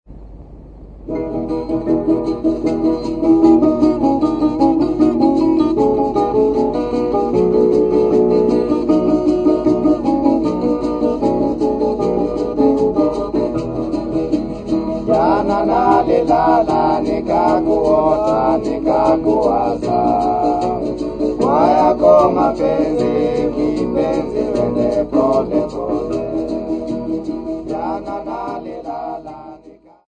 Folk music--Africa
Field recordings
Africa Kenya Yala f-ke
A rumba song accompanied by guitars and a rattle.